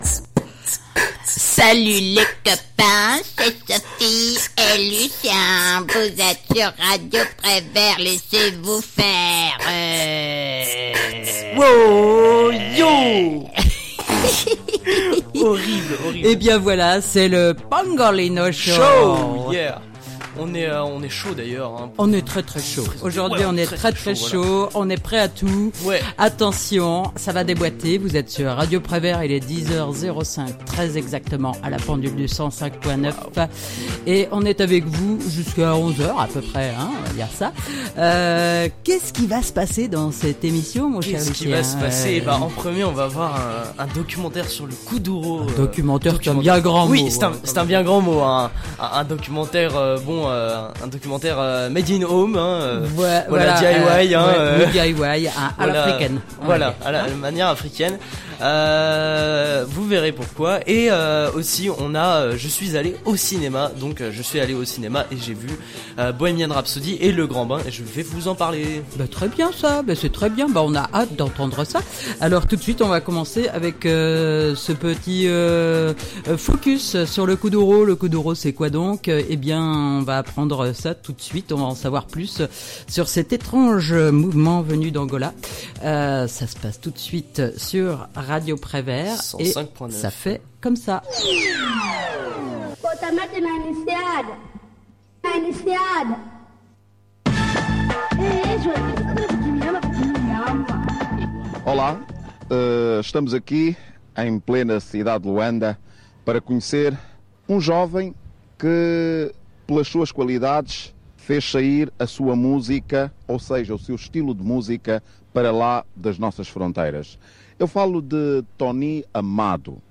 en direct